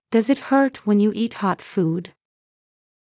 ﾀﾞｽﾞ ｲｯﾄ ﾊｰﾄ ｳｪﾝ ﾕｳ ｲｰﾄ ﾎｯﾄ ﾌｰﾄﾞ